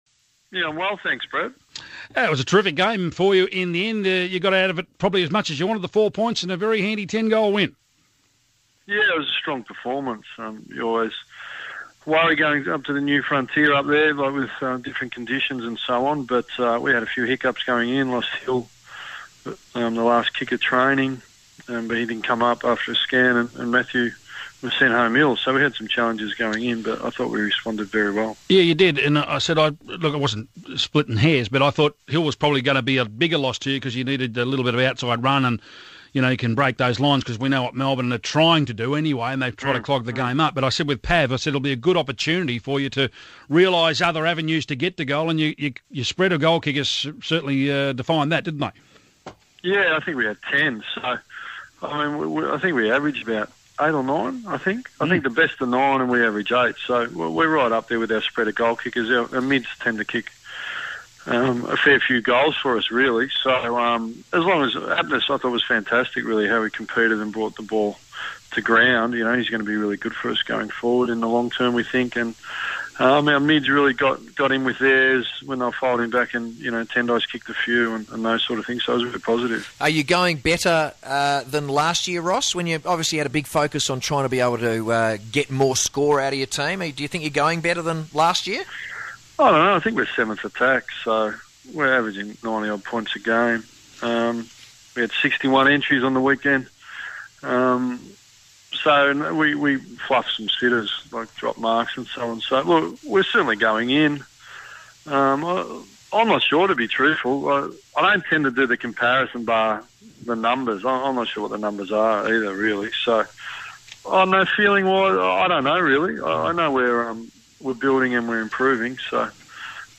Ross Lyon speaks to 6PR Sports Today about the injury cloud over midfielder, Stephen Hill.